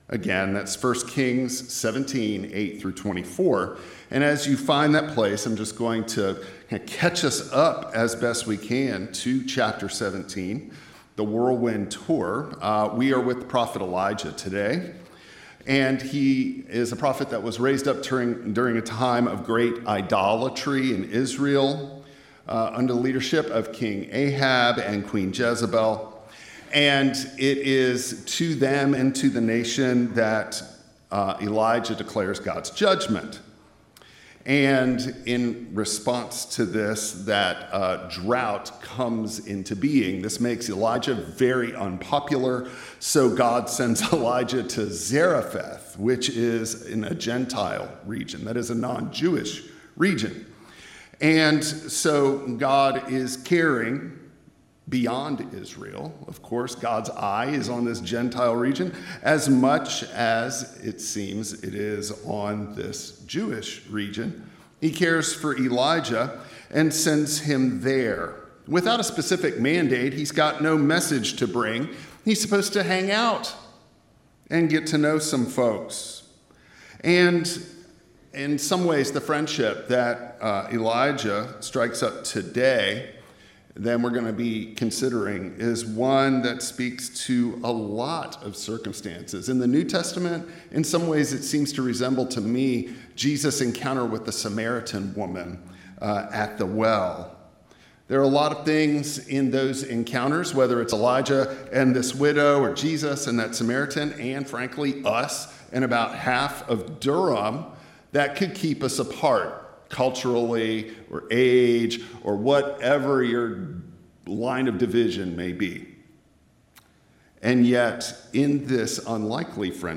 1 Kings 17:8-24 Service Type: Traditional Service What if your scarcity is where generosity begins?